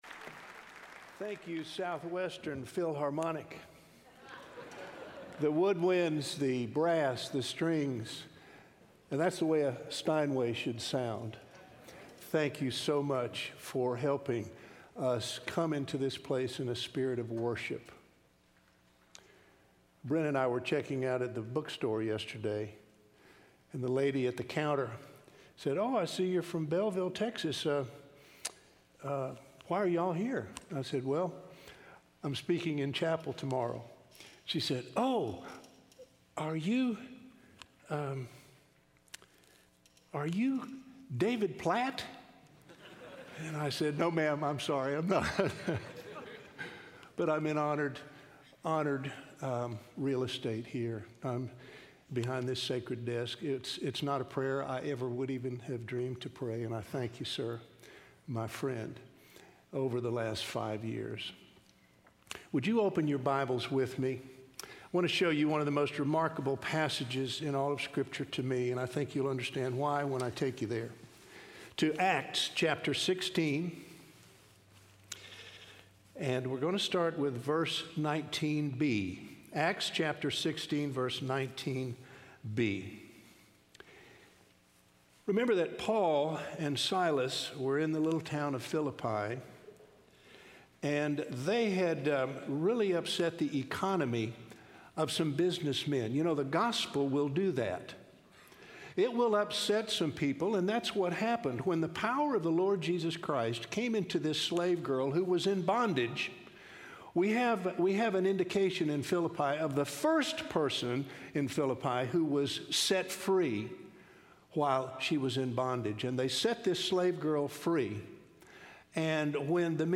in SWBTS Chapel